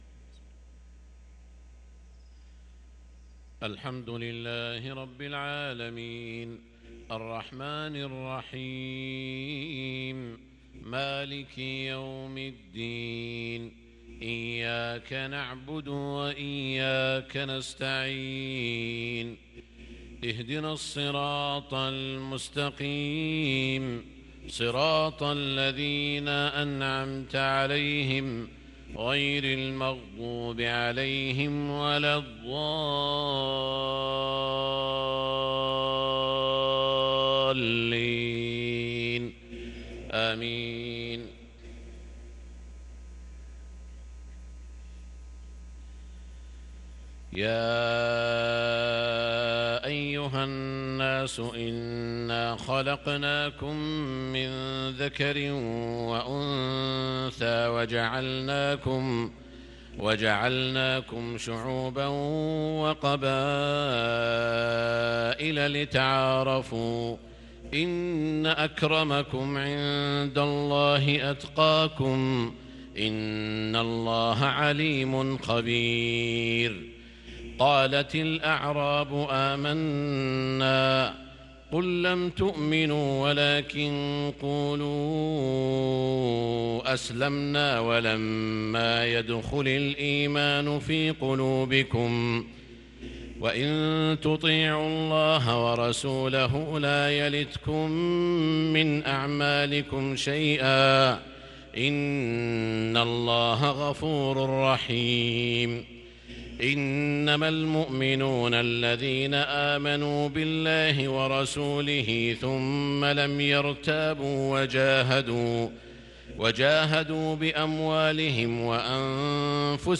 صلاة المغرب للقارئ سعود الشريم 6 ربيع الأول 1444 هـ